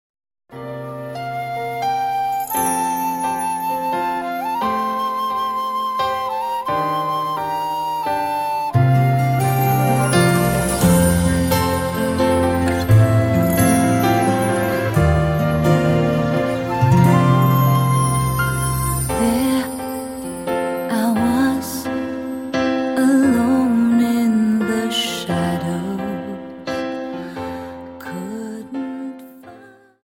Dance: Slow Waltz 29 Song